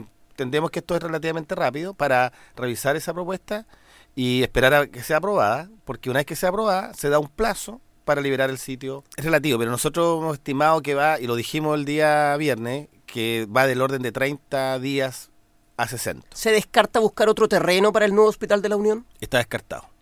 En entrevista con Radio Bío Bío, el delegado Presidencial, Jorge Alvial, descartó que el Gobierno evalúe cambiar el terreno para la ubicación del proyecto y precisó que tras la aprobación del plan de rescate, los trabajos para el nuevo hospital de La Unión podrían retomarse dentro de 60 días.